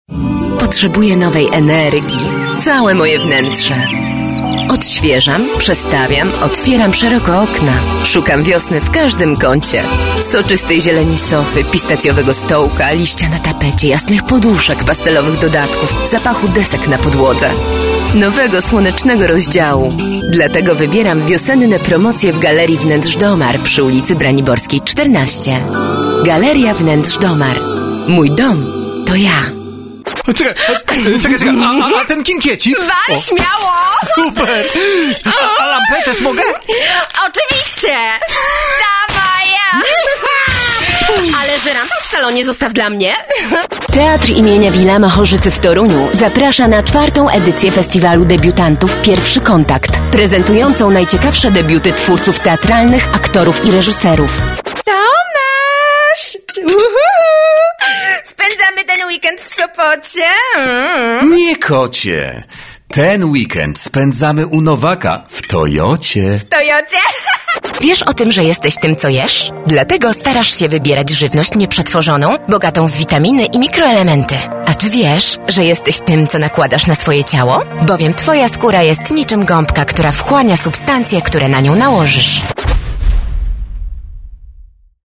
Female 20-30 lat
Warm, feminine voice. Ability to read in a lowered, intriguing tone, but also higher-pitched sounding more girlish. Comfortable in comedy sketches.
Nagranie lektorskie